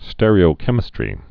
(stĕrē-ō-kĕmĭ-strē, stîr-)